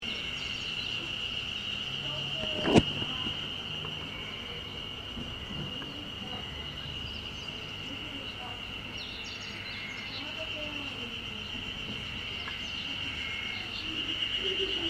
エゾハルゼミの声が賑やかに聞こえる日でした。
先日紹介しましたエゾハルゼミの鳴き声はあまりはっきりしていませんでしたので、もう一度、鳴き声を添付致します。
エゾハルゼミの鳴き声.mp3